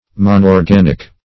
Search Result for " monorganic" : The Collaborative International Dictionary of English v.0.48: Monorganic \Mon`or*gan"ic\, a. [Mon- + organic.]